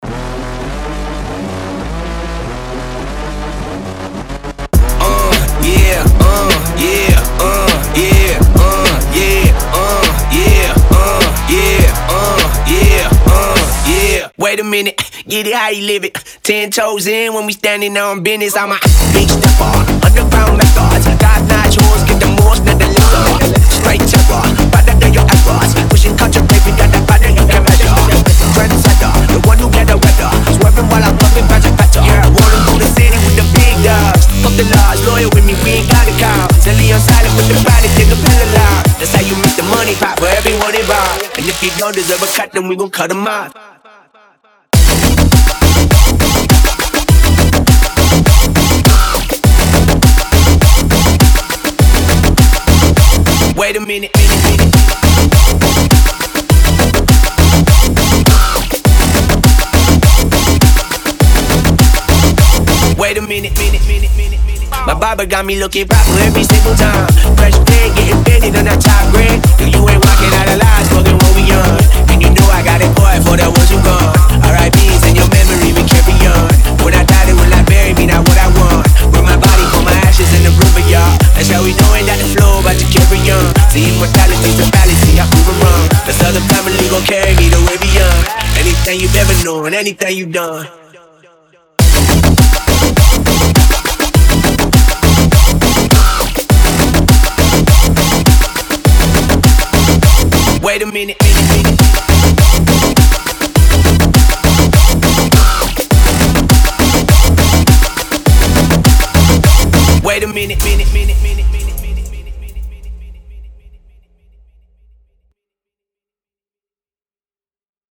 Dubstep Mix